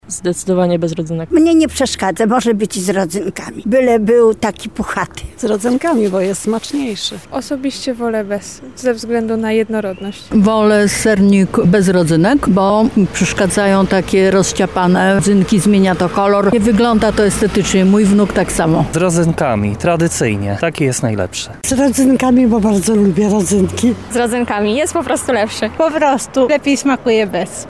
[SONDA] Czy sernik z rodzynkami nadal wzbudza kontrowersje?
Zapytaliśmy lublinian, czy zjedliby sernik, gdyby znajdowały się  na nim kontrowersyjne rodzynki.
Sernik sonda